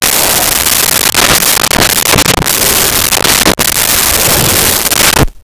Gorilla Roars And Breaths 02
Gorilla Roars And Breaths 02.wav